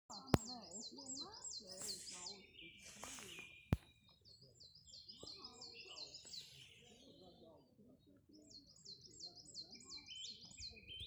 Coal Tit, Periparus ater
Ziņotāja saglabāts vietas nosaukumsBauskas nov. Baldone
StatusSinging male in breeding season
NotesJauktu koku mežs